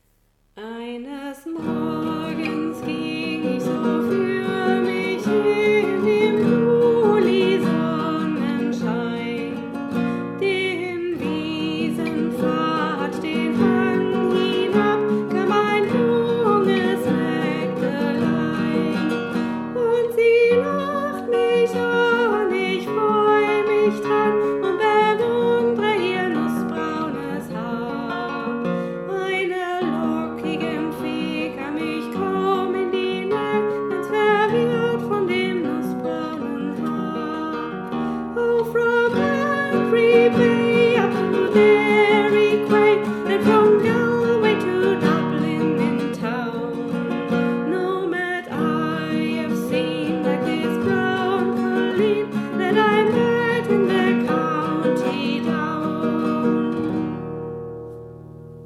Dieses Lied wurde vom Sextett der Freien Waldorfschule Halle eingesungen.